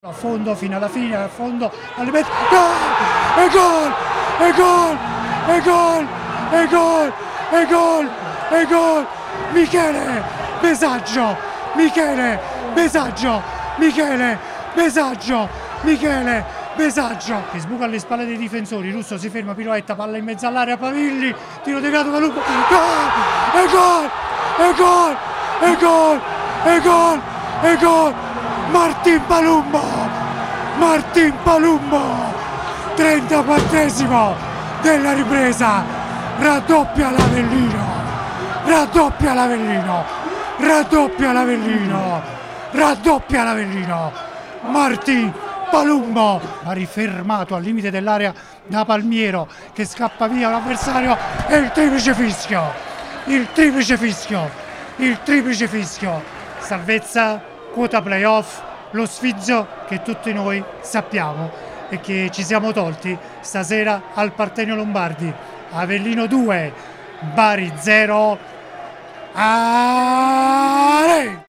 PODCAST | AVELLINO-BARI 2-0: RIASCOLTA L’ESULTANZA